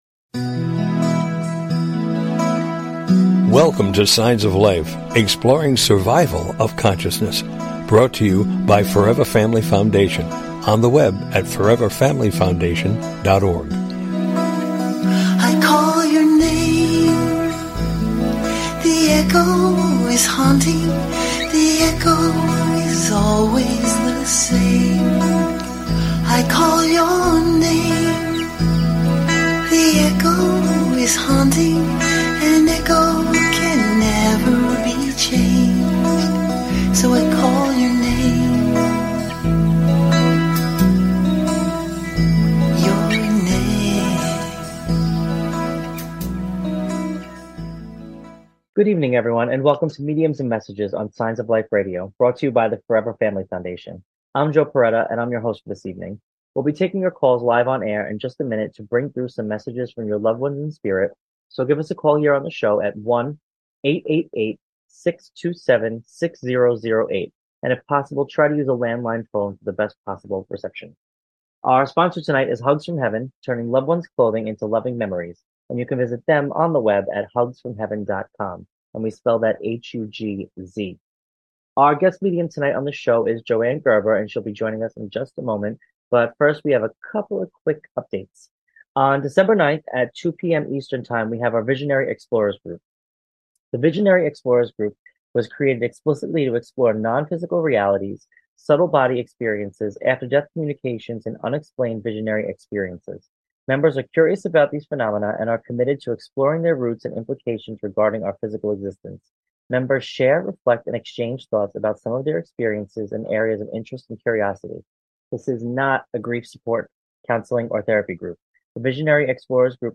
This Show Format invites listeners to call in for "mini readings."